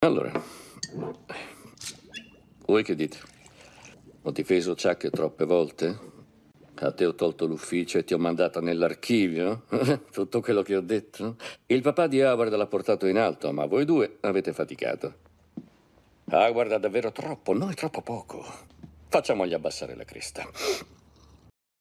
nel telefilm "Better Call Saul", in cui doppia Patrick Fabian.